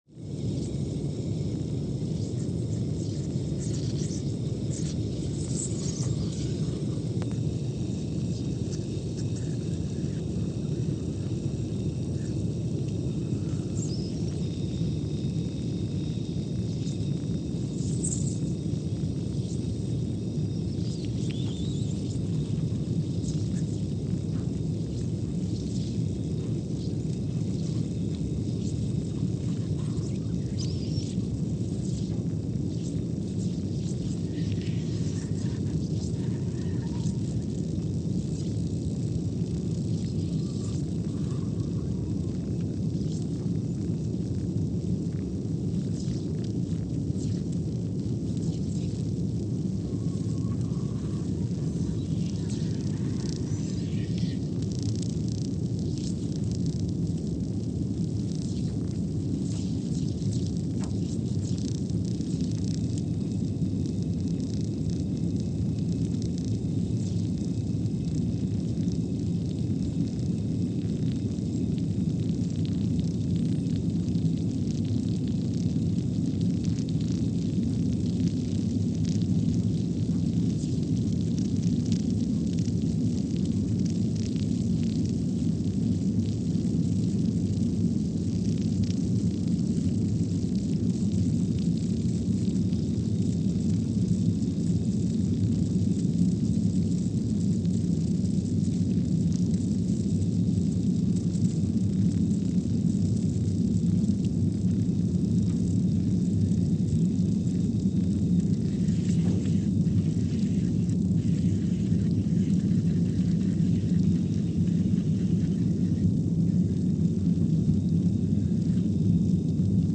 Scott Base, Antarctica (seismic) archived on January 28, 2021
Sensor : CMG3-T
Speedup : ×500 (transposed up about 9 octaves)
Loop duration (audio) : 05:45 (stereo)
Gain correction : 25dB